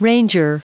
Prononciation du mot ranger en anglais (fichier audio)
Prononciation du mot : ranger